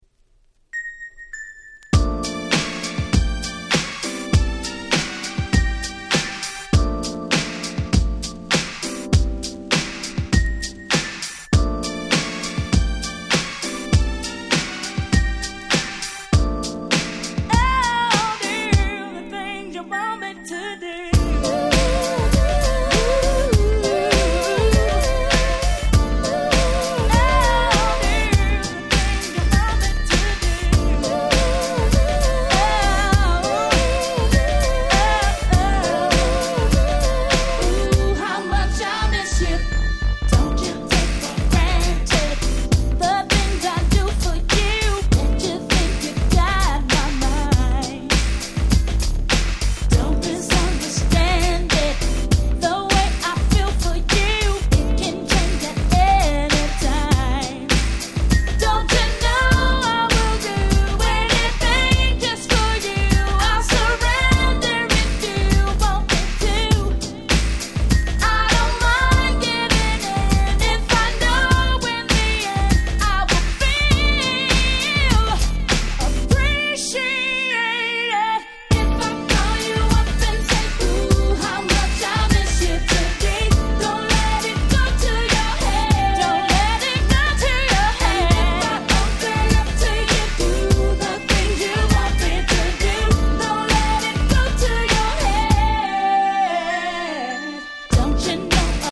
US Promo Only Remix !!
正直ベチャネバ並みの破壊力とまではいきませんが、ズッシリしたBeatが気持ちの良い良曲です。